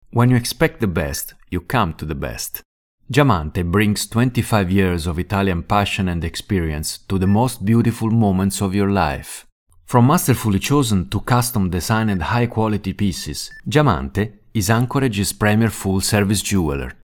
Amichevole, professionale e business-oriented
Sprechprobe: Werbung (Muttersprache):